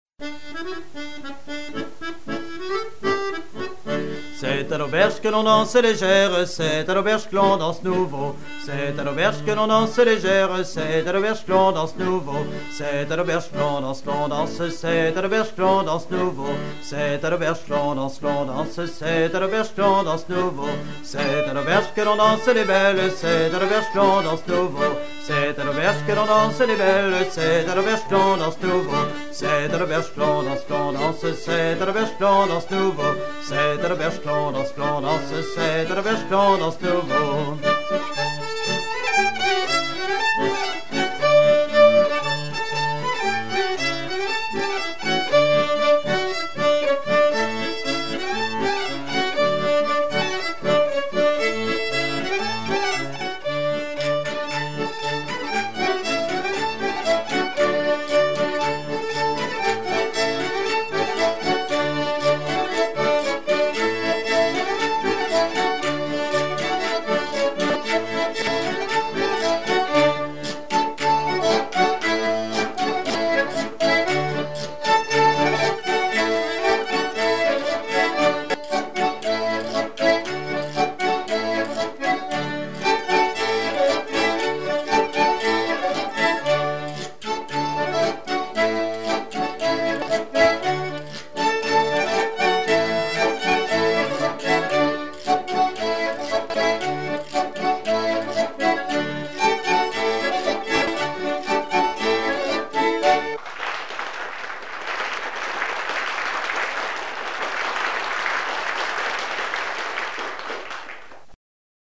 Couplets à danser
branle : courante, maraîchine
Pièce musicale éditée